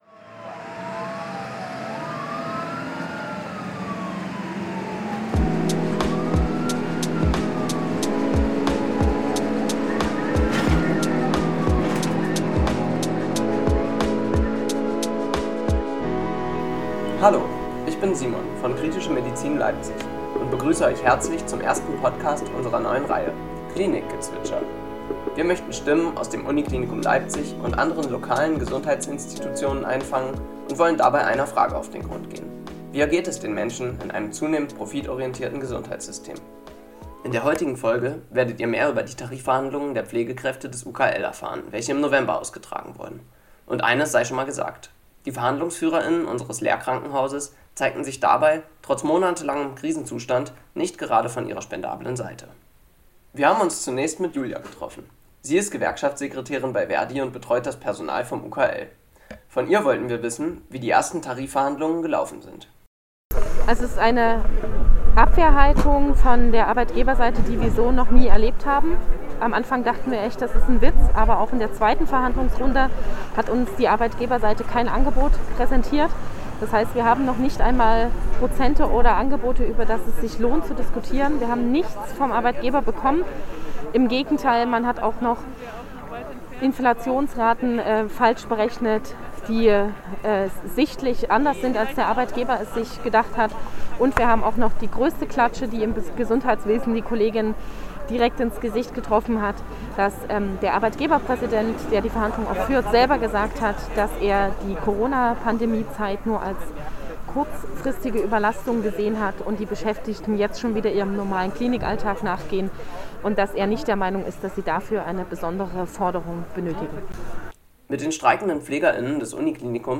Am 25. November streikten die Pflegekräfte des UKL zwischen 11 und 13 Uhr in der Liebigstraße in Form einer aktiven Mittagspause im Rahmen der Tarifrunde der Länder. Wir interviewten die Streikenden, welche uns von ihren Gründen sich im Arbeitskampf zu engagieren und den Bedingungen im Krankenhaus unter zunehmendem Kostendruck berichteten.